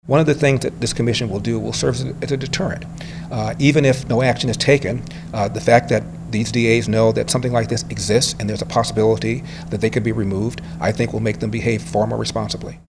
Republican presidential candidate Larry Elder spoke on behalf of the PAC at a Des Moines news conference and began by criticizing local prosecutors in Los Angeles, Chicago, Boston, Baltimore, and Philadelphia.